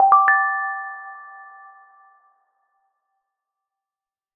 dm_sent.ogg